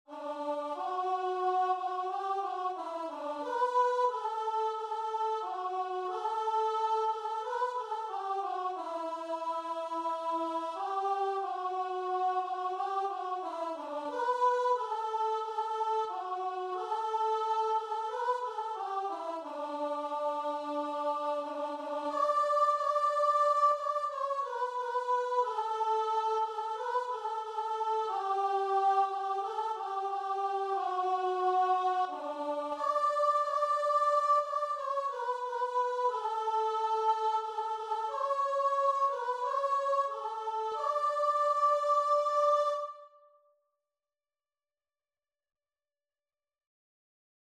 Christian Christian Guitar and Vocal Sheet Music I Know Whom I Have Believed
Free Sheet music for Guitar and Vocal
D major (Sounding Pitch) (View more D major Music for Guitar and Vocal )
4/4 (View more 4/4 Music)
Classical (View more Classical Guitar and Vocal Music)